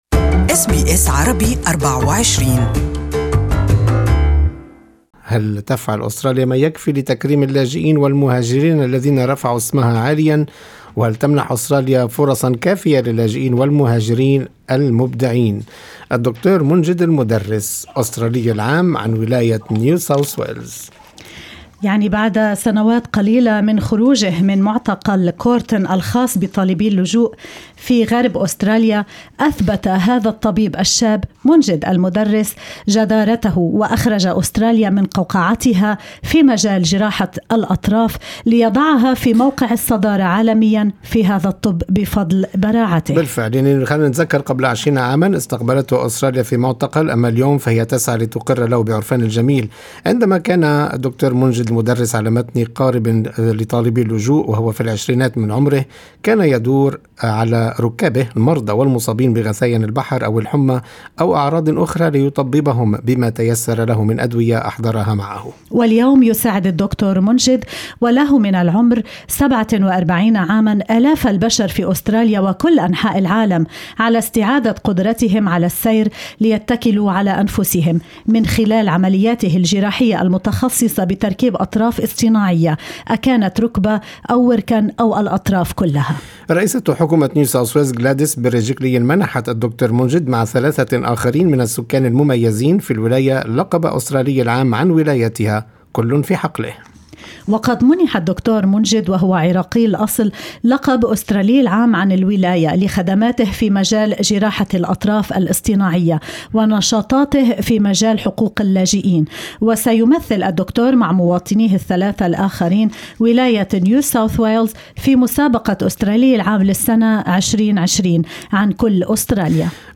اتصالٌ واحد كان كافياً ليجعله يترك ما كان يقوم به وينضم إلينا في لقاء مباشر على الهواء.